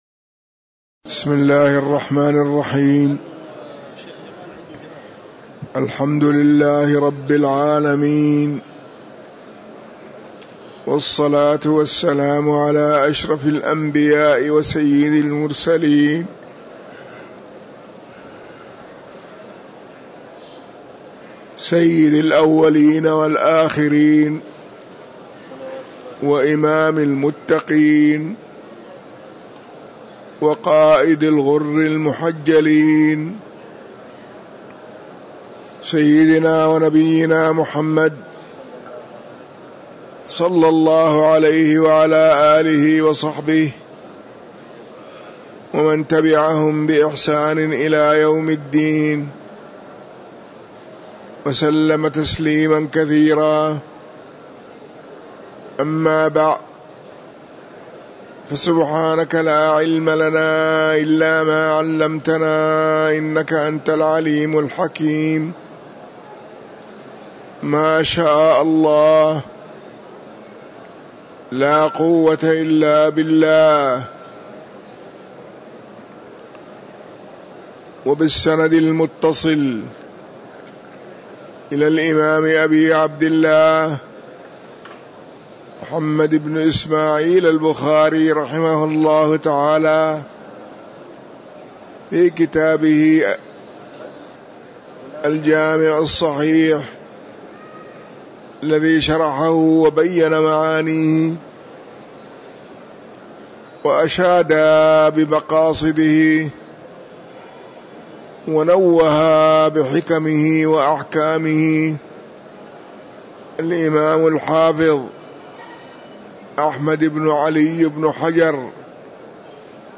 تاريخ النشر ٣ جمادى الأولى ١٤٤٤ هـ المكان: المسجد النبوي الشيخ